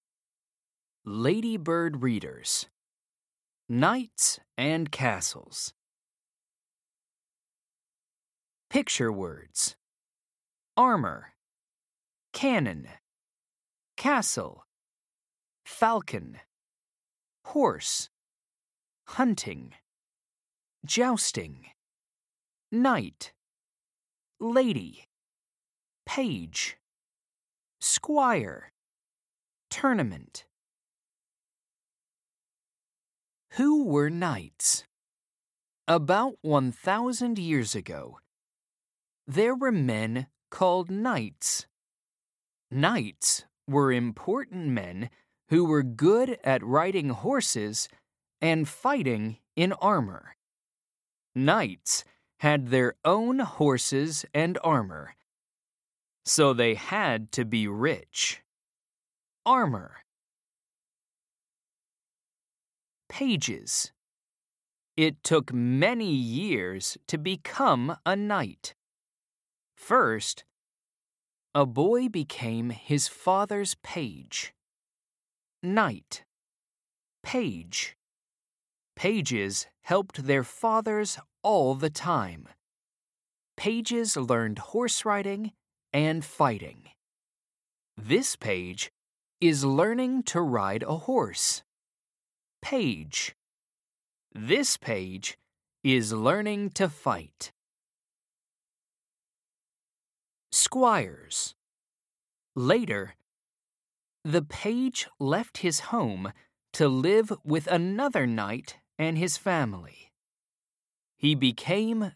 1.-Knights-and-Castles-Reader-UK-LADYBIRD-READERS.mp3